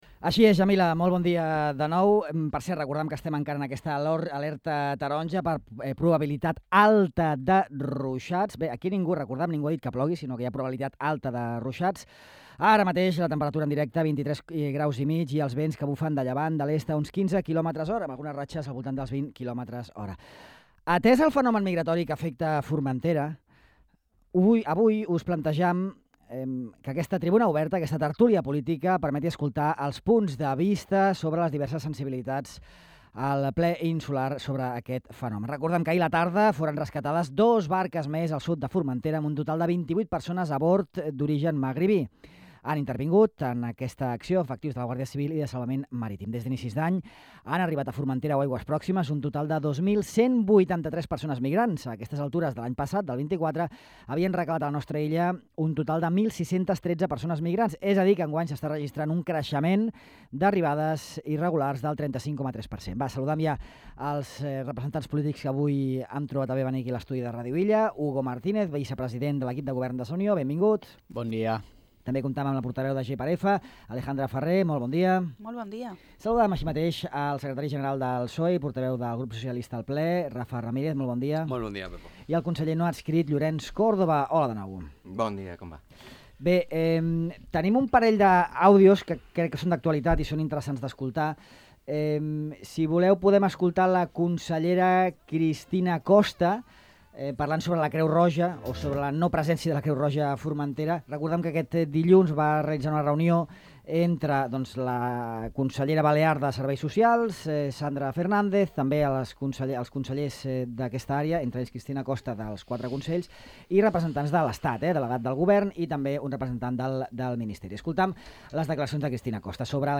La migració, a la tertúlia política amb Sa Unió, GxF, PSOE i Córdoba